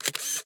cameraShutterClick.ogg